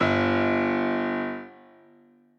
b_basspiano_v100l1o2g.ogg